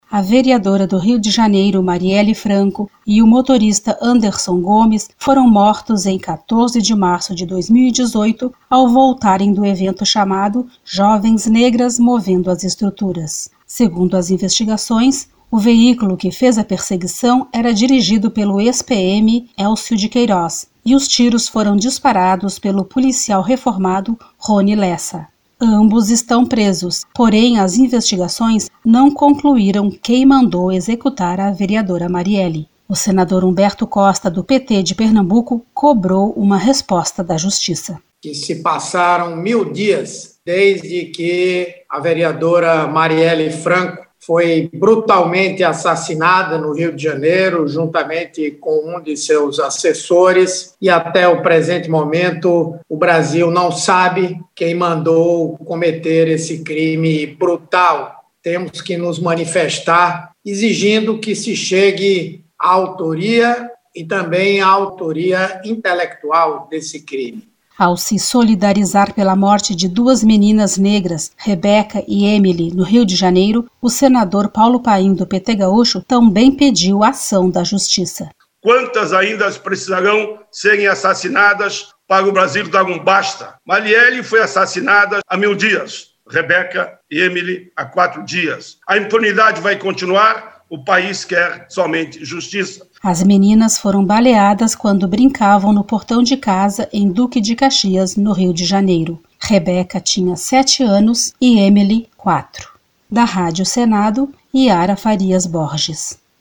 Em 14 de março de 2018, os dois foram mortos a tiros, quando retornavam do evento Jovens Negras Movendo as Estruturas, no Rio de Janeiro. Os senadores Humberto Costa (PT-PE) e Paulo Paim (PT-RS) cobraram a investigação e punição dos mandantes do crime.